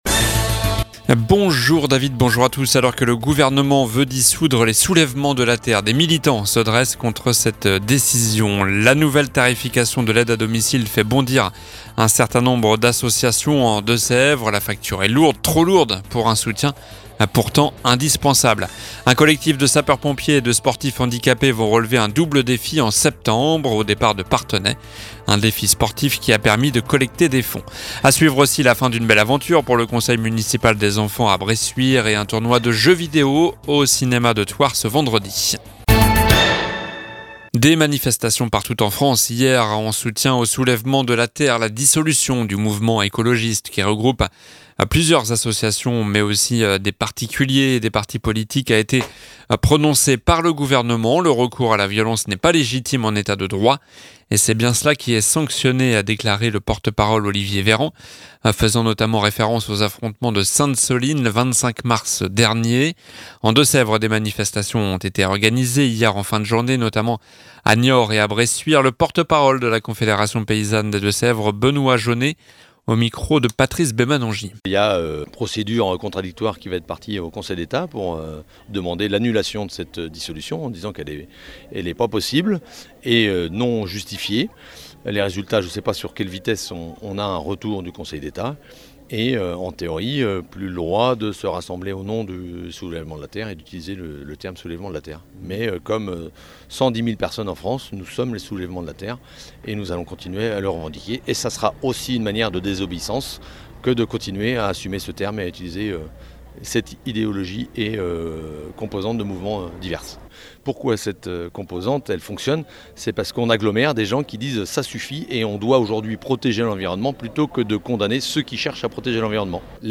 Journal du jeudi 22 juin (midi)